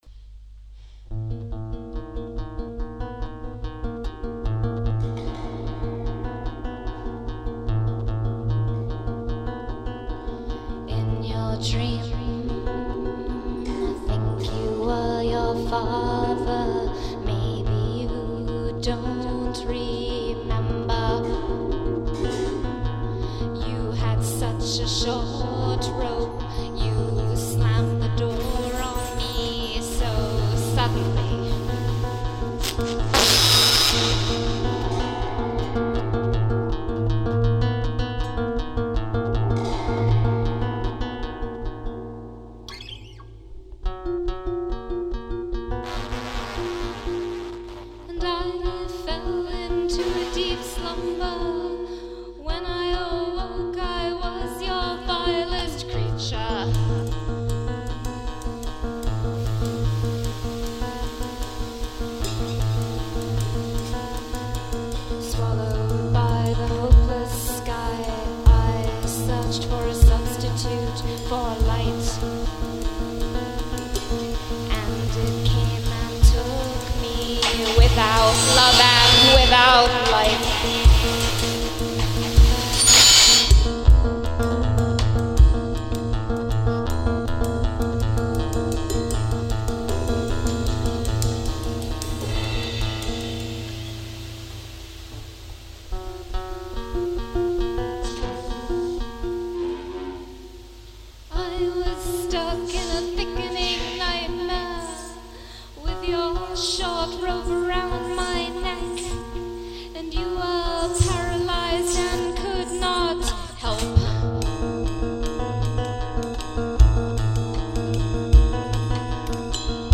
psycho folk
live concert